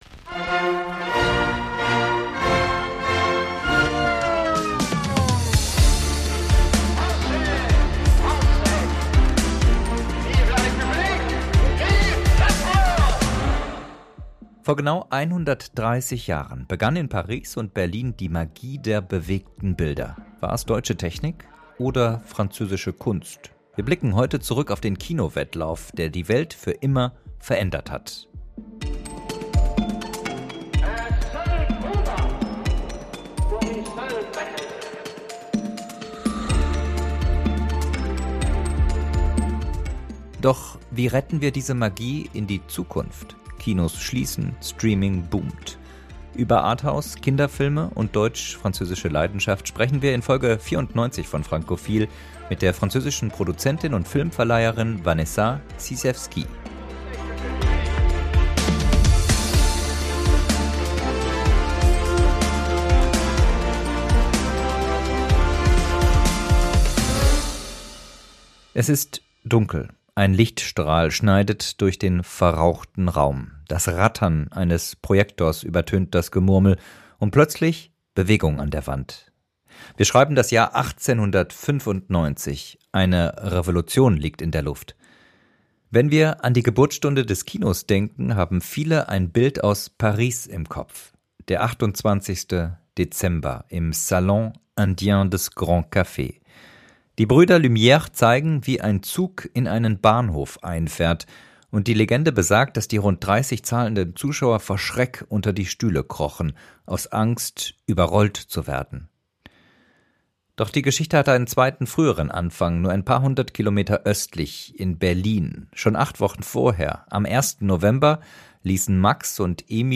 Wir sprechen mit ihr über Arthouse-Kino, warum Kinderfilme die Hoffnung der Branche sind und wie man die Magie des "kollektiven Träumens" in die Zukunft rettet.